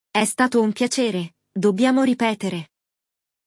No episódio de hoje, vamos acompanhar um diálogo entre um homem e uma mulher que começaram a conversar pelas redes sociais e decidiram se conhecer pessoalmente.